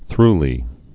(thrlē)